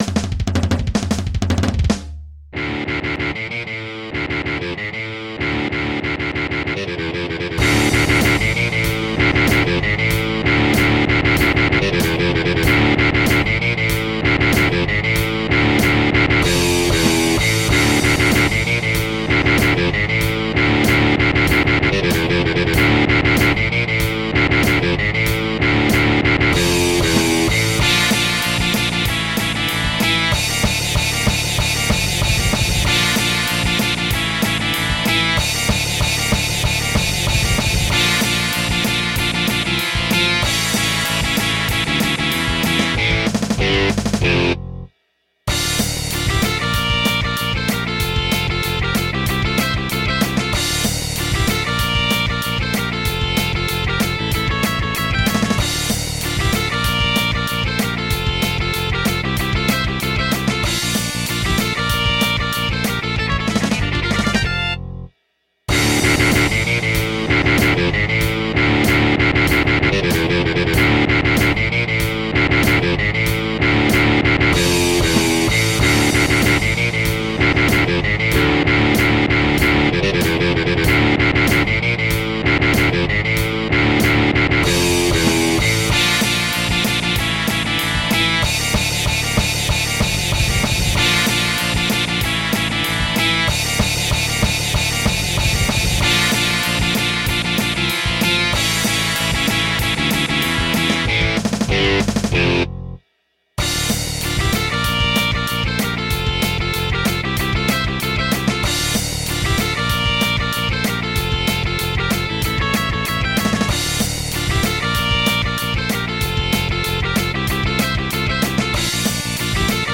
MIDI 58.38 KB MP3
is a hard rock song